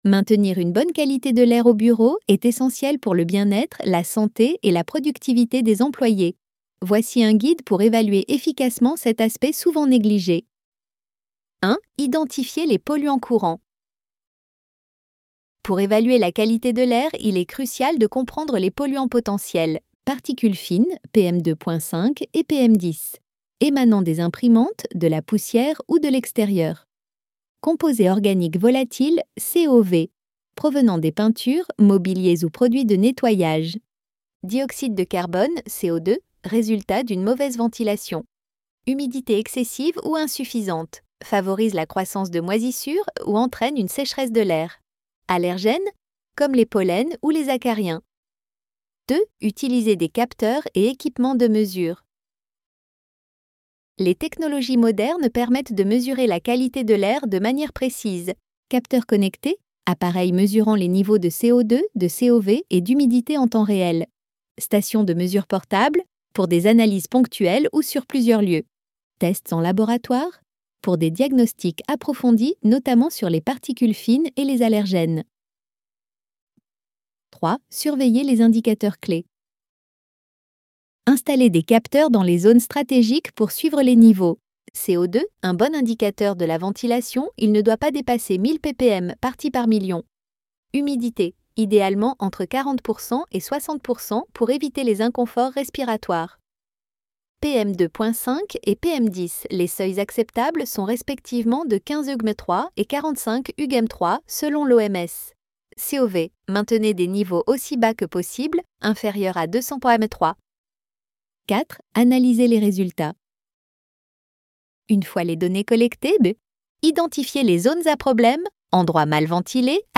ElevenLabs_Chapter_1-16.mp3